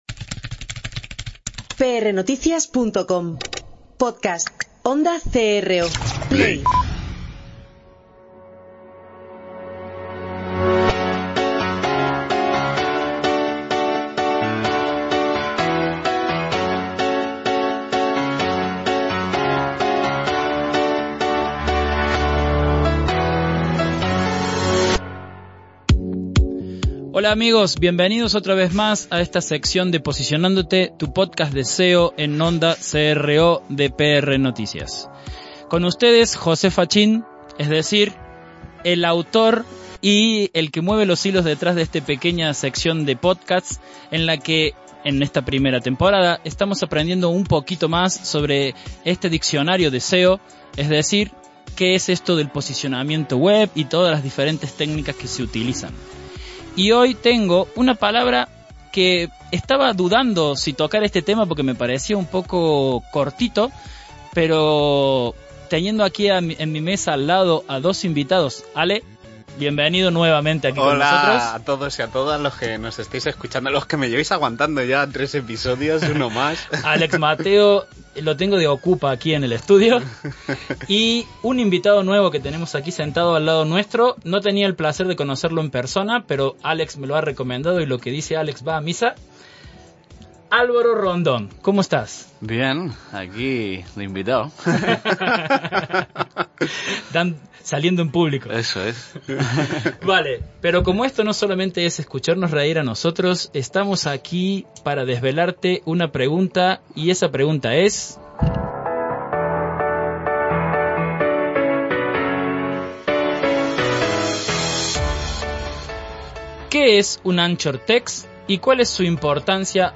Así que, entre los tres intentaremos debatir e intentar haceros comprender mejor las preguntas que tienes.